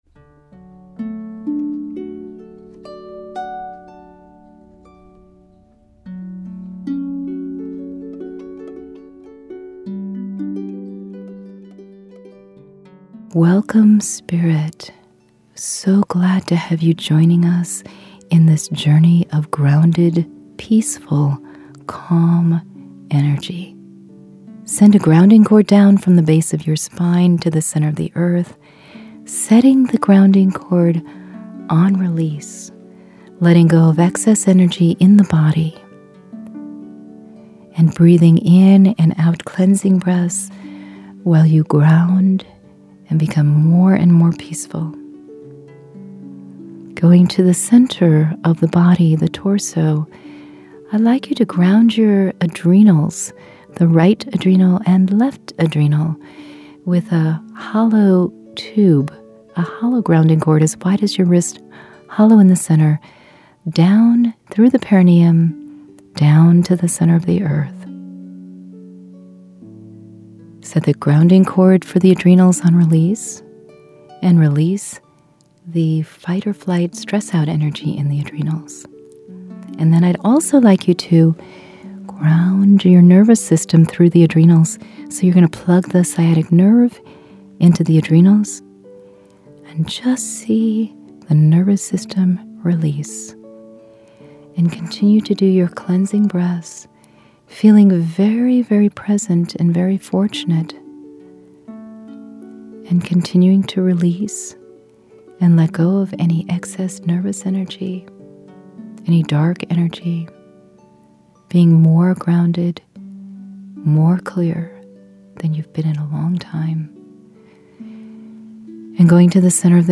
Do you want to feel calm, blissful, and serene? This Q5 Grounded and Calm meditation will help center you.